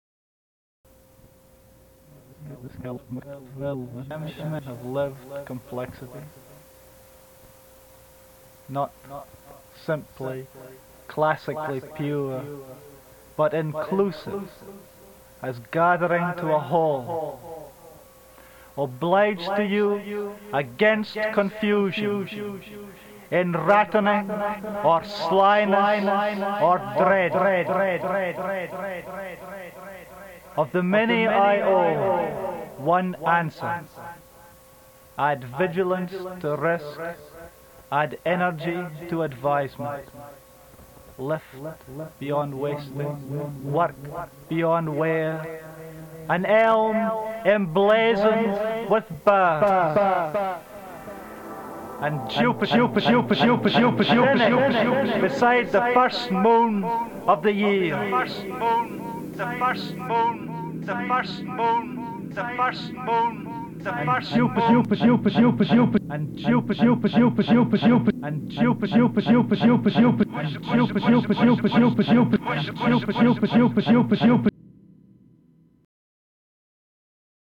§ There's a lot of echo on some of these -- blame it on Elvis and the Memphis slap back sound.
We were after the experimental, the migration into electronic sound-text narrative, where noise is just as important as language.
I was using an Akai GX 280D which had stereo echo, unlike the ReVox A77 which had mono echo, so the Akai was an advance in terms of spacial imaging.
This is an echo loop extravaganza.